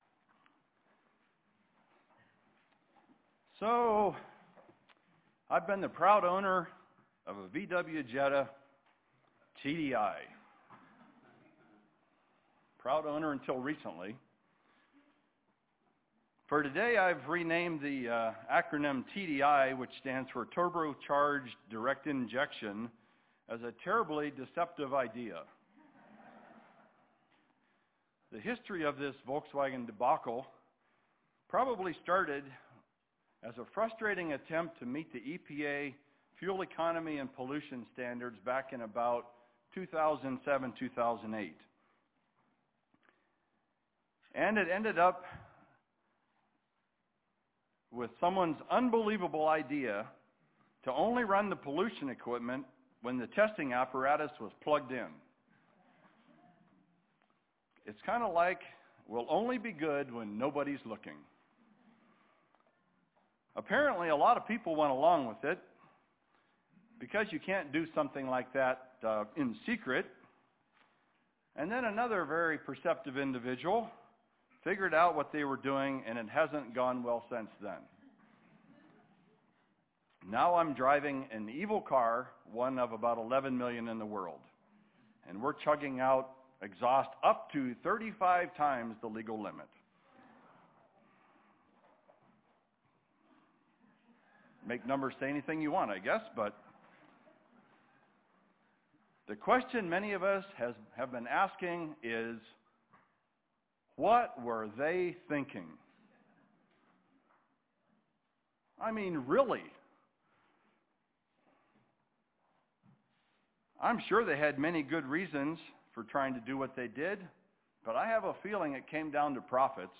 Given in Spokane, WA
UCG Sermon Studying the bible?